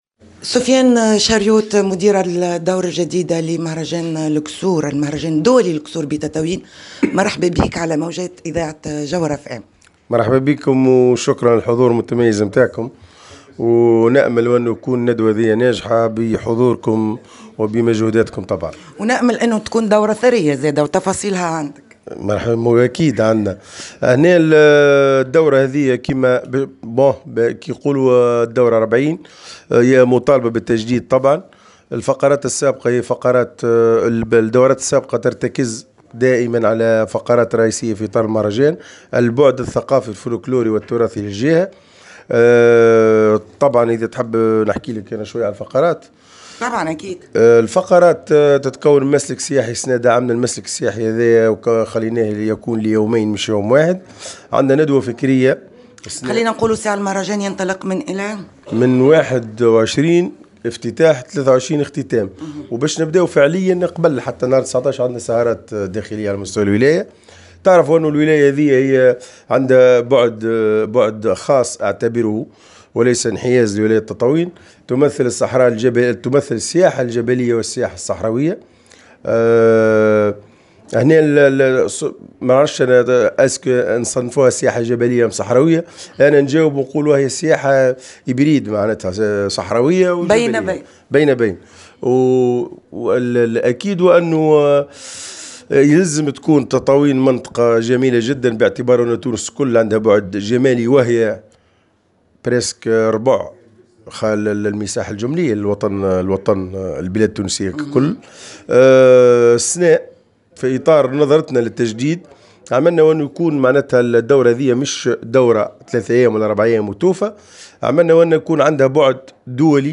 تفاصيل الدورة 40 لمهرجان الدولي للقصور الصحراوية بتطاوين (تصريح)